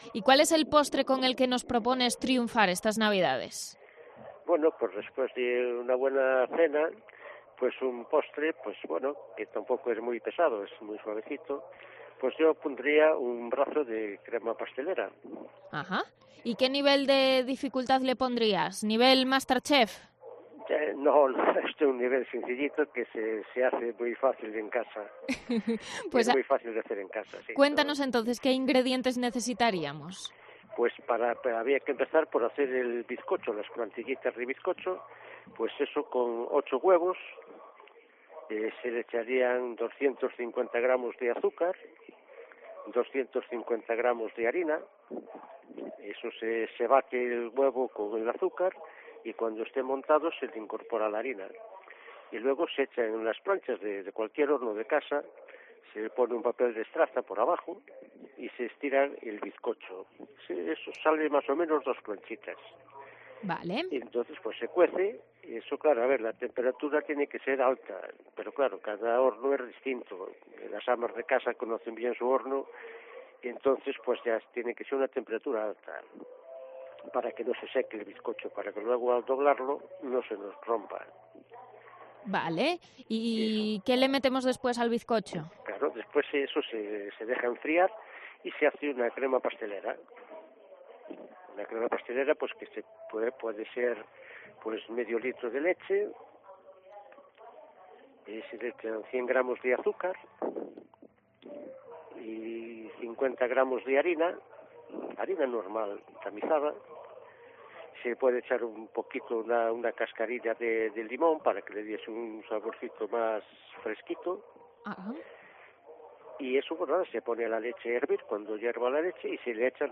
Paso a paso, el maestro de obrador guía al oyente en las dos elaboraciones necesarias para sorprender con un brazo de crema pastelera hecho en casa.